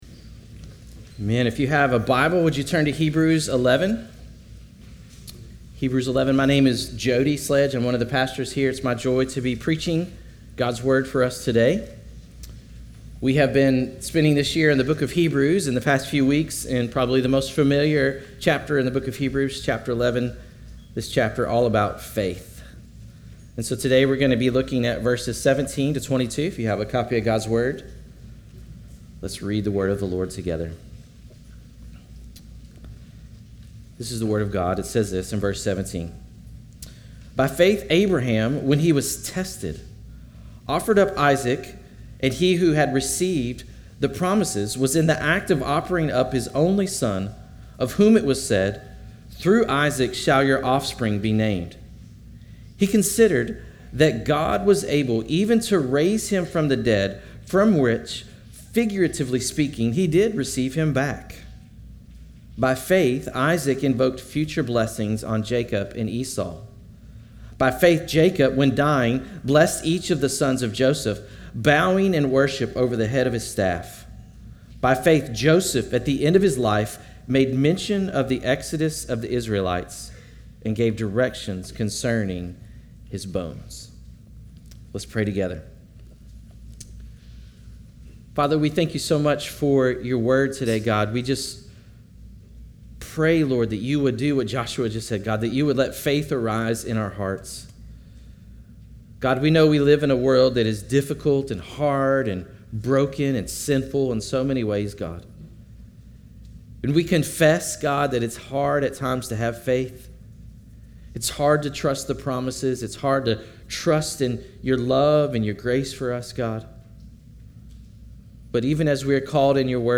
Christ Fellowship Sermons